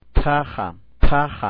Tabla I: Alfabeto Oficial sonorizado
Oclusivas aspiradas ph